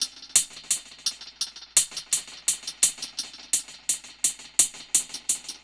Db_DrumKitC_Wet_EchoHats_85-03.wav